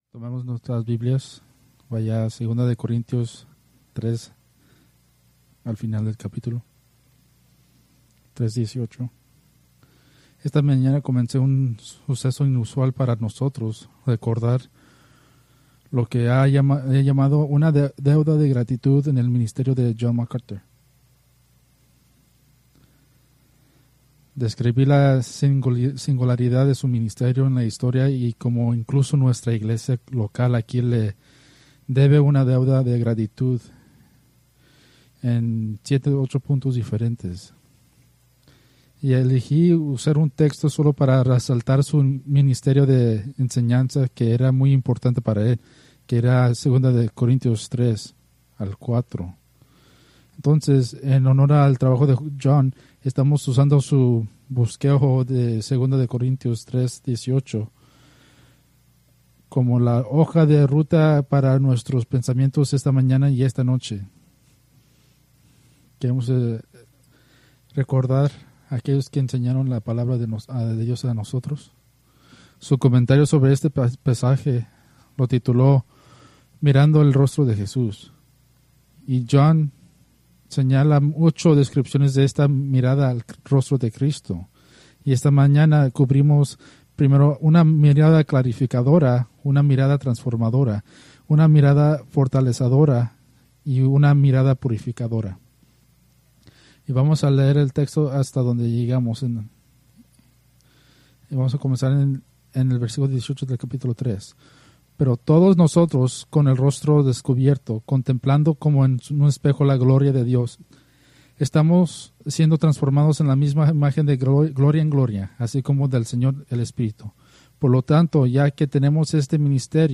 Preached July 27, 2025 from 2 Corintios 3:18-4:1-6